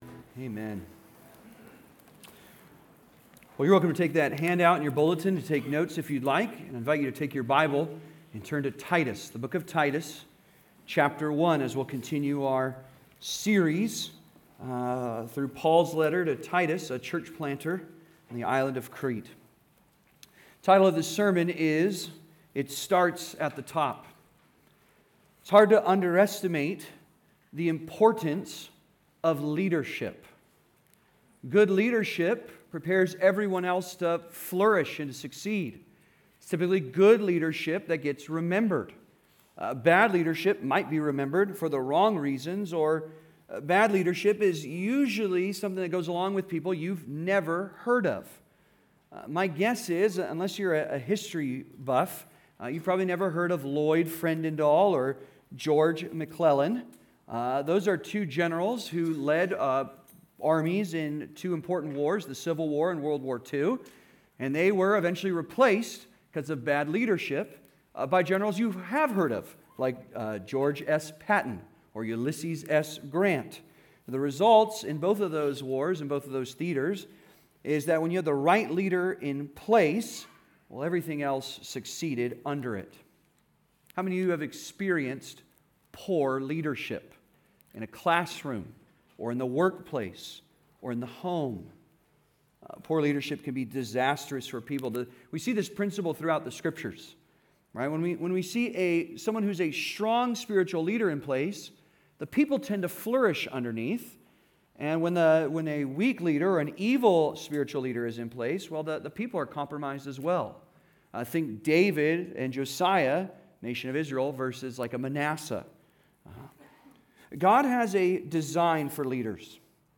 It Starts at the Top (Sermon) - Compass Bible Church Long Beach